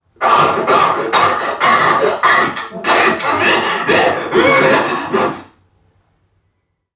NPC_Creatures_Vocalisations_Robothead [75].wav